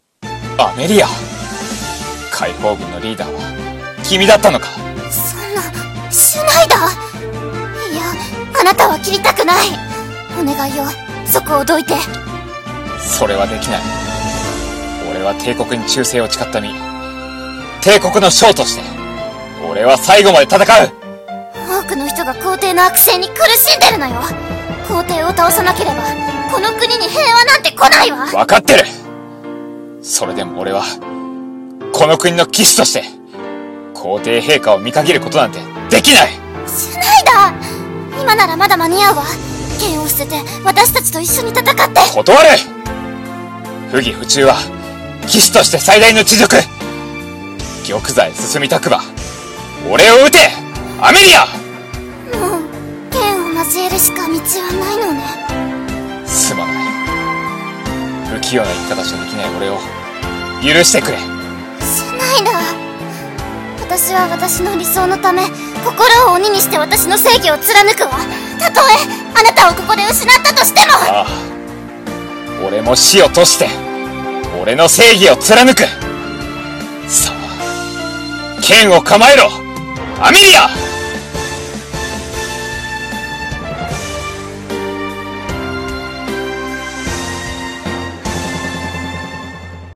【声劇台本】死を賭して【掛け合い声劇】